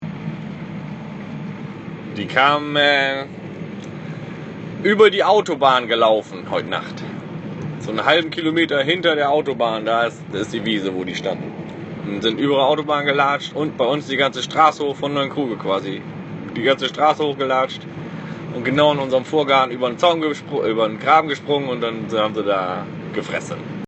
interview-kuehe-westerholtsfelde.mp3